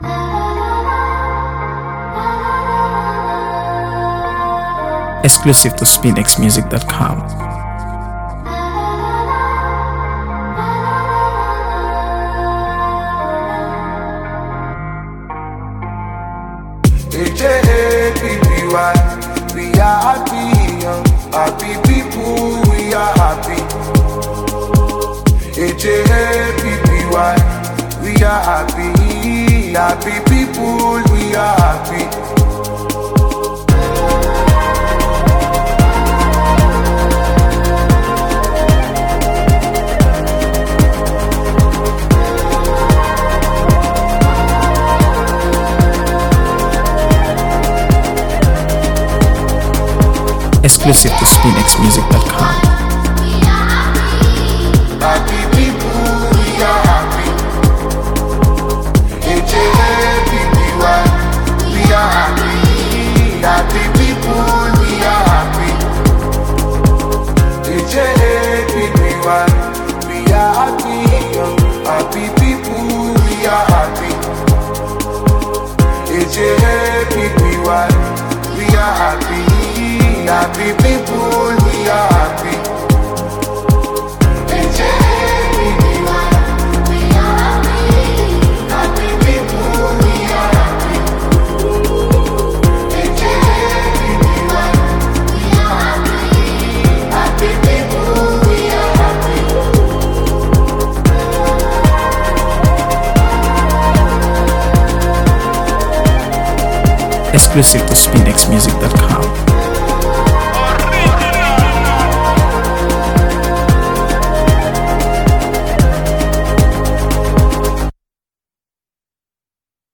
AfroBeats | AfroBeats songs
With its vibrant rhythm and feel-good lyrics